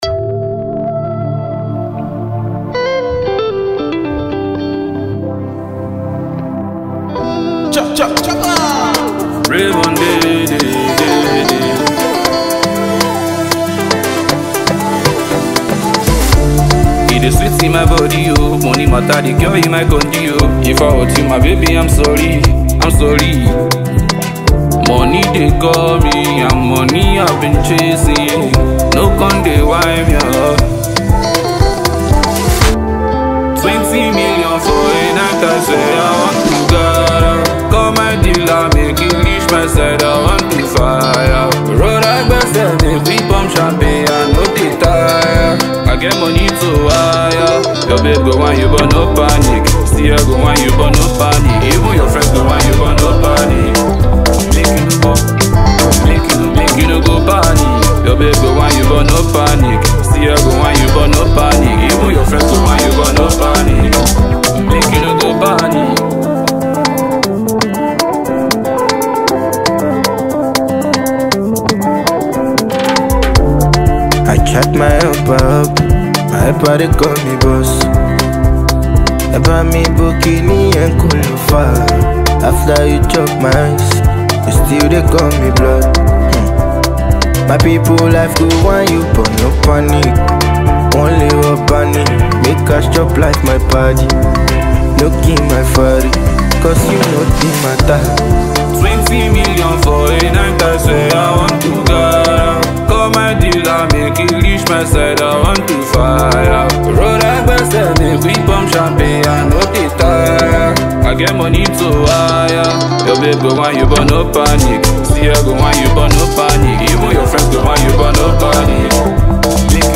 melodic new tune